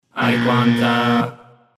Play, download and share IQWANTTA BUZZER original sound button!!!!
iquanta-buzzer.mp3